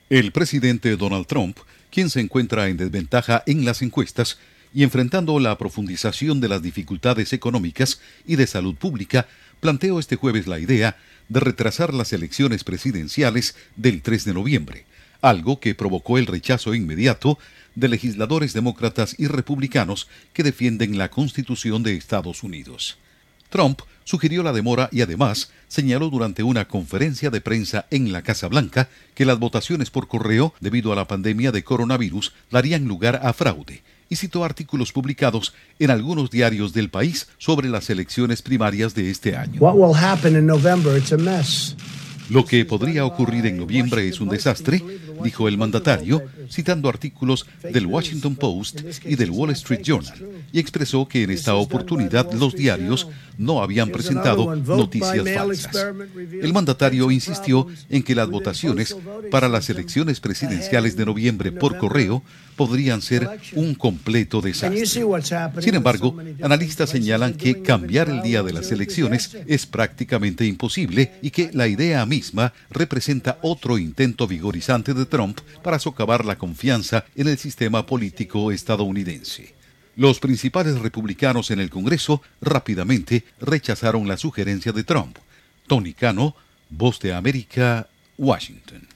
Trump advierte sobre posibilidad de fraude si las elecciones presidenciales de EE.UU. se realizan por correo. Informa desde la Voz de América en Washington